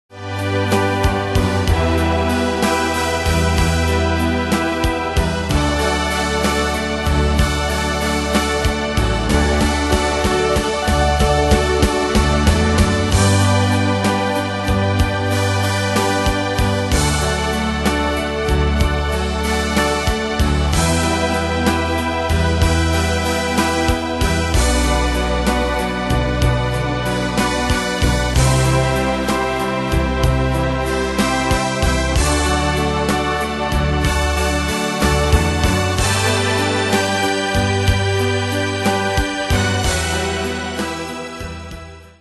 Style: Oldies Ane/Year: 1965 Tempo: 63 Durée/Time: 4.14
Danse/Dance: Ballade Cat Id.
Pro Backing Tracks